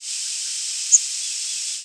hypothetical Yellow Warbler nocturnal flight call
Hypothetical identification based on the species' temporal migration pattern and abundance, and on the frequency and modulation characteristics of the call.